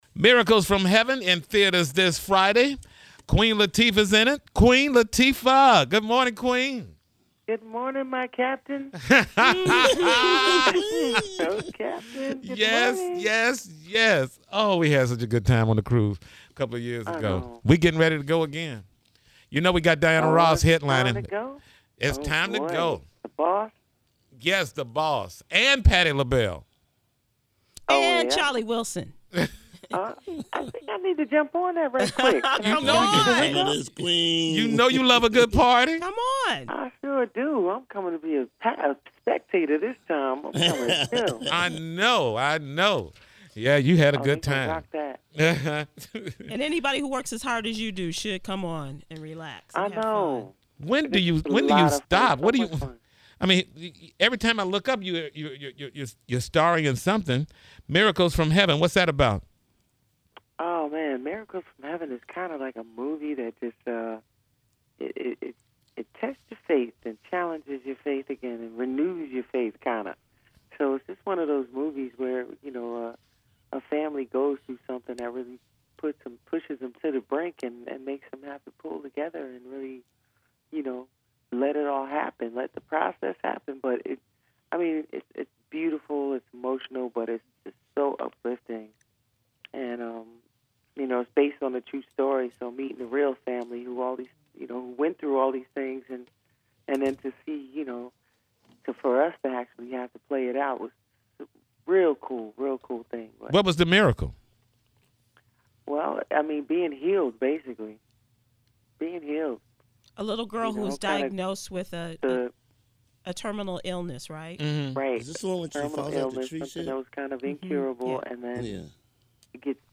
Miracles From Heaven is in theaters this Wednesday, March 16thClick the link above to hear the entire interview.